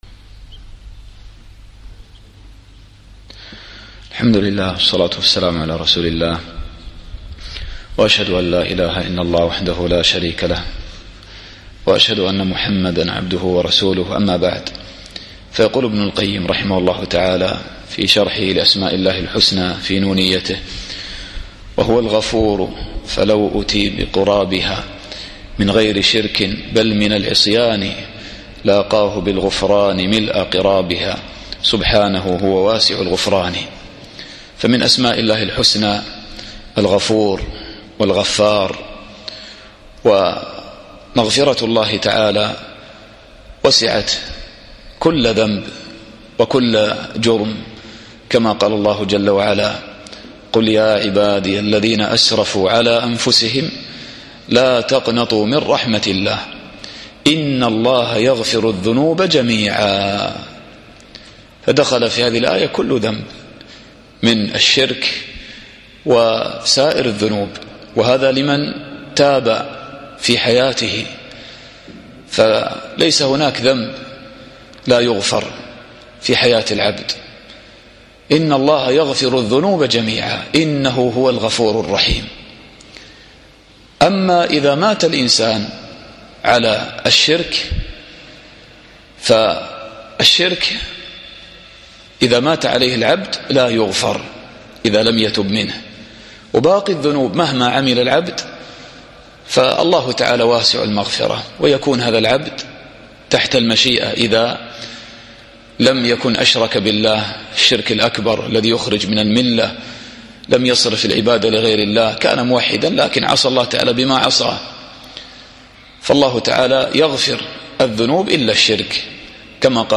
الدرس الحادي والثلاثون